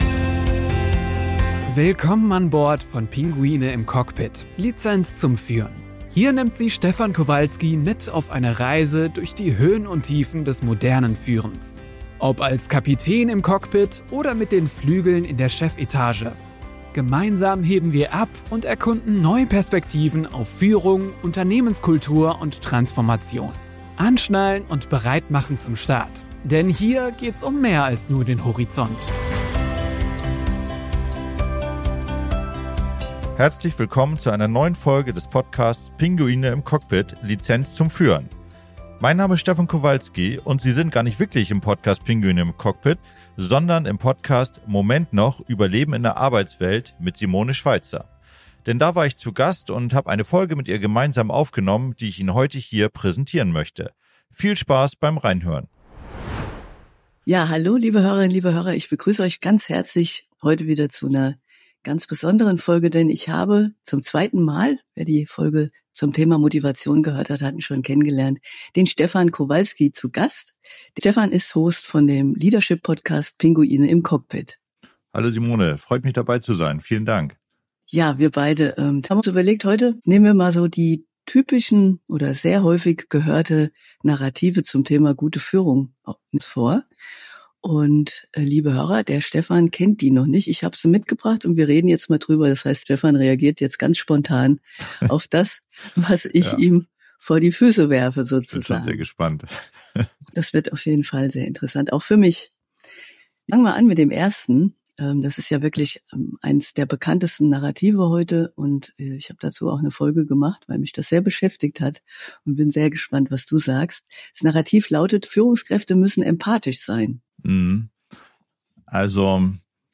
Die Folge 27 ist eine Gast-Folge – aber umgekehrt.